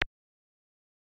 click3.ogg